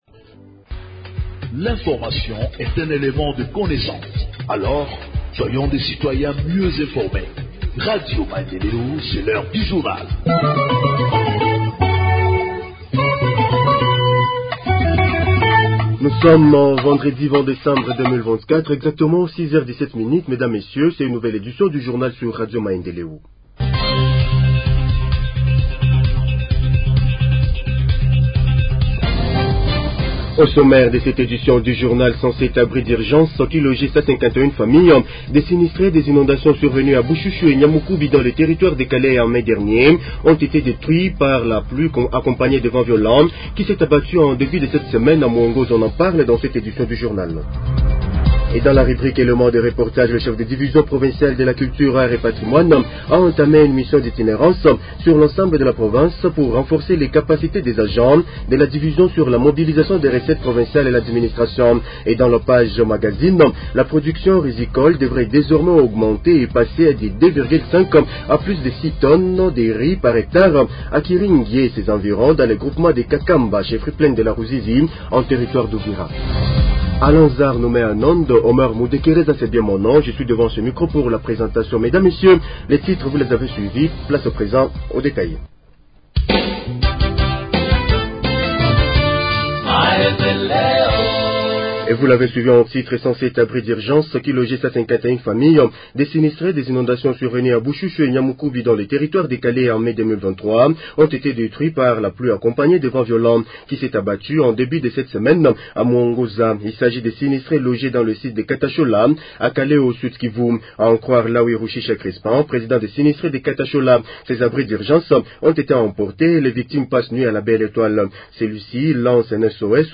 Journal en Français du 20 Décembre 2024 – Radio Maendeleo